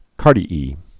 (kärdē-ē)